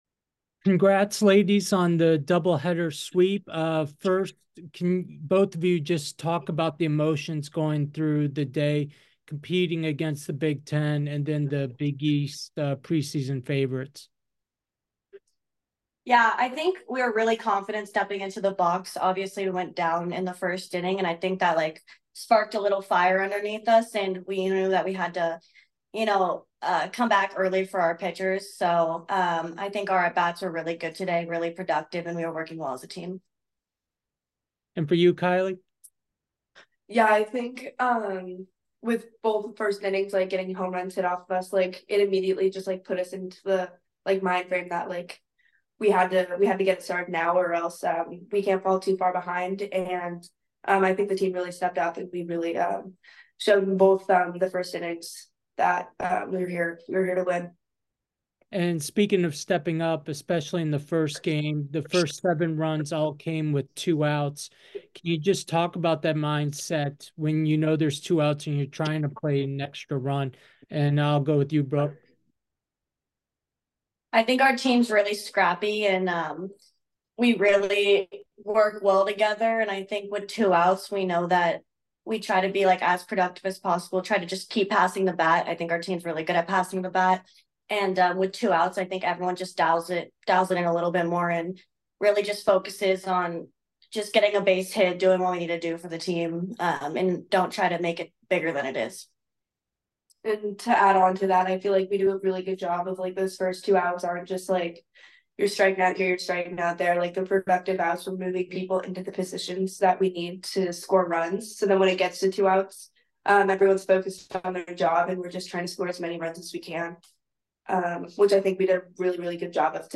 Duke Invitational Day 1 Postgame Interview